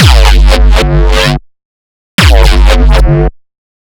failing at recreating a mefjus bass.wav